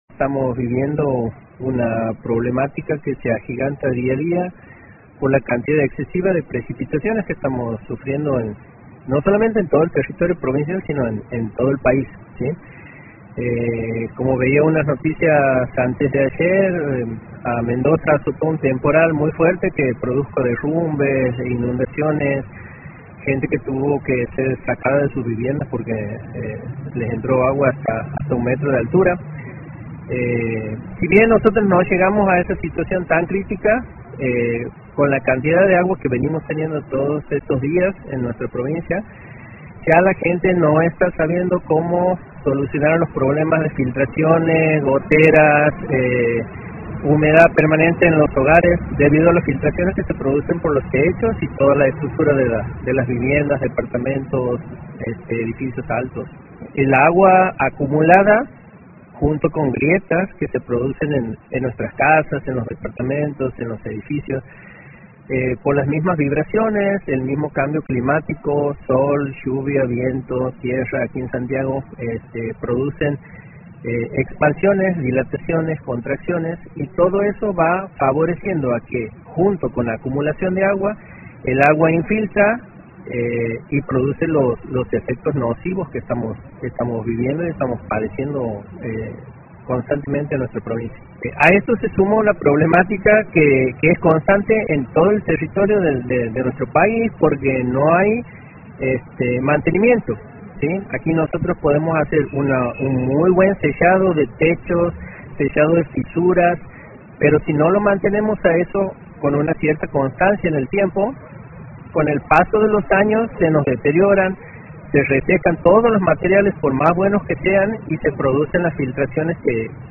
dialogó con Noticiero 7